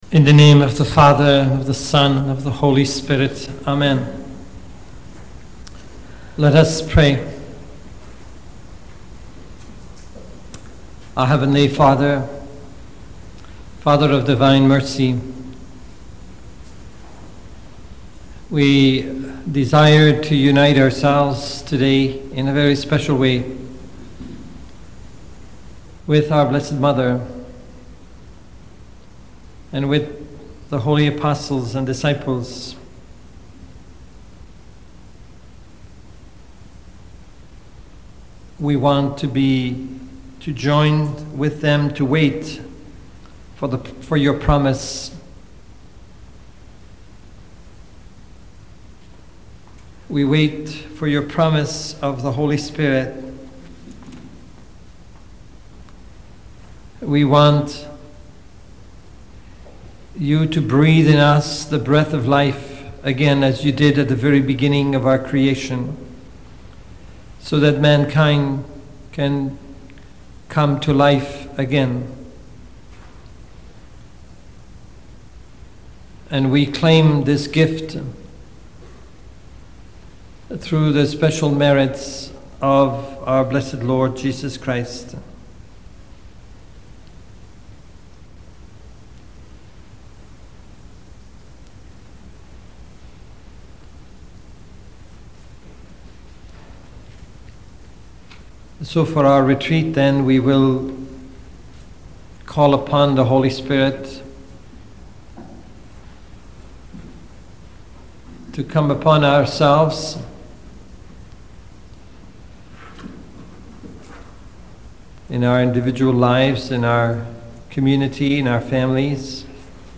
Pentecost Talk given June 2000 on the Sequence for Penecost Sunday